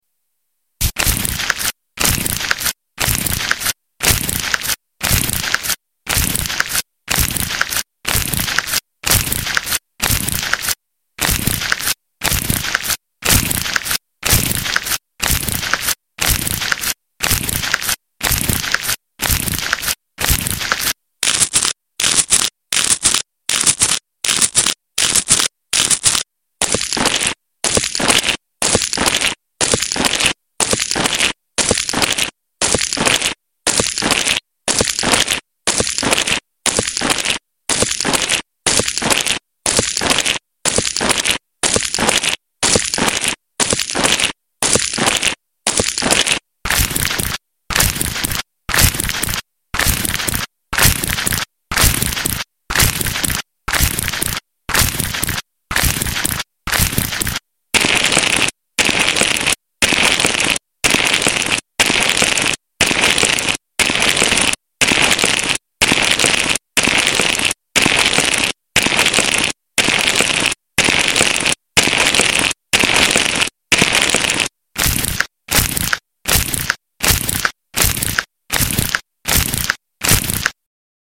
ASMR foot spa & care sound effects free download
ASMR foot spa & care |gentle pampering sounds for sleep& street relief